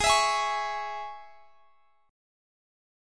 Listen to G#sus4#5 strummed